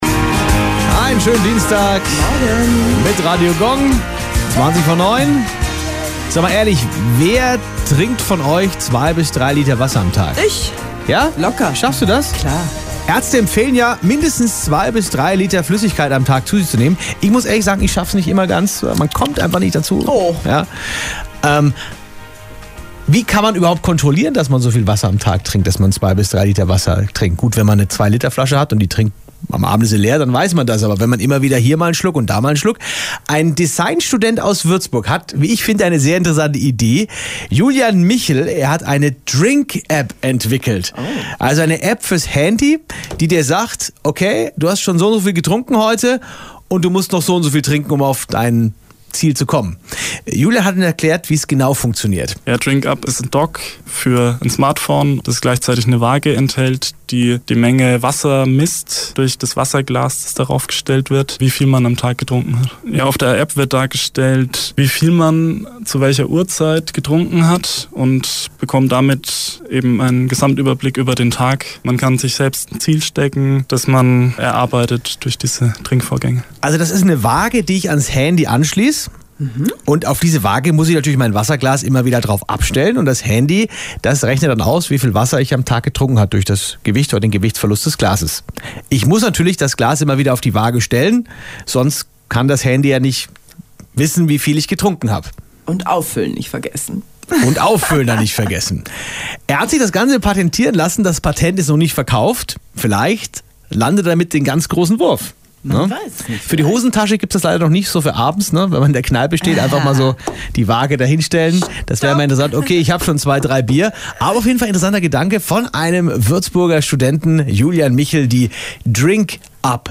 30.9.2014 Interview bei